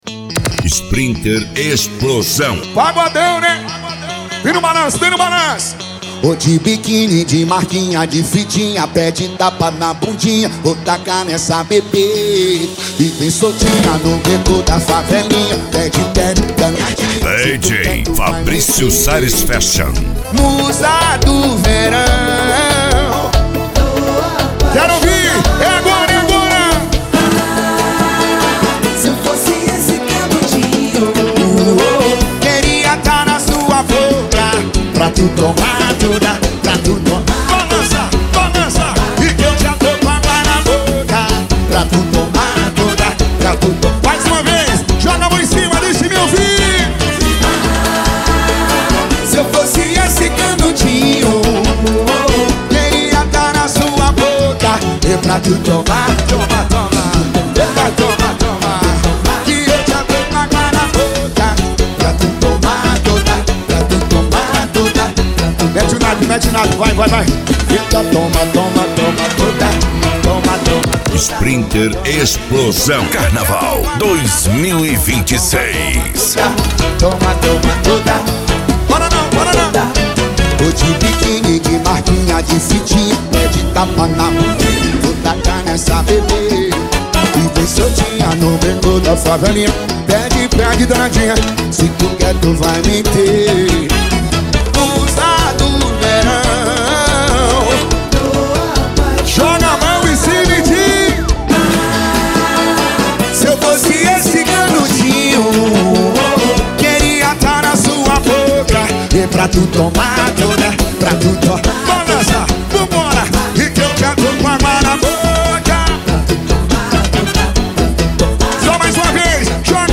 Arrocha
Funk